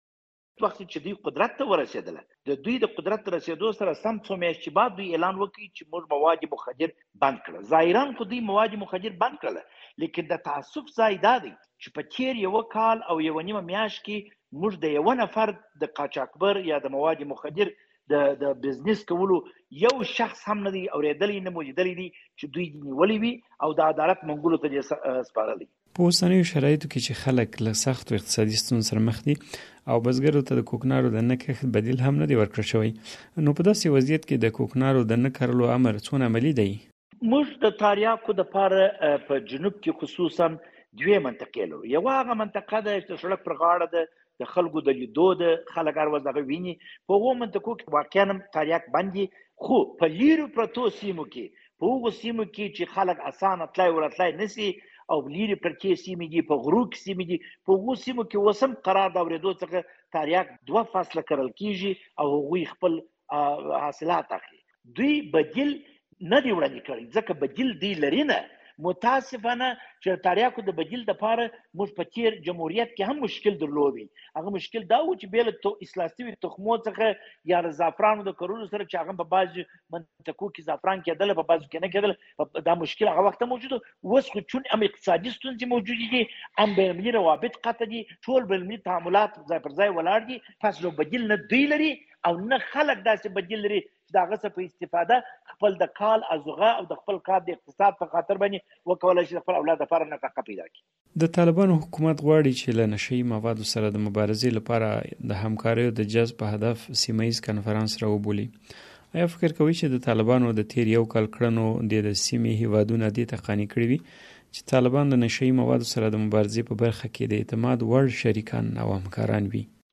دا چې د طالبانو حکومت د ګاونډيو هېوادونو د همکارۍ جلبولو لپاره د سيمې په کچه کنفرانس جوړوي، په دې اړه مو د ولسي جرګې له پخواني غړي خالد پښتون سره مرکه کړې.